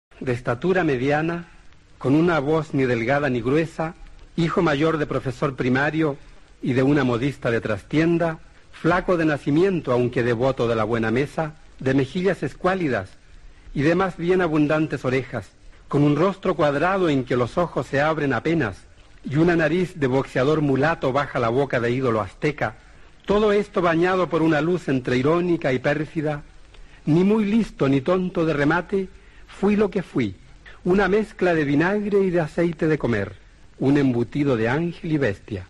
Escucha a Nicanor Parra, el poeta chileno fallecido a los 103 años, recitando un epitafio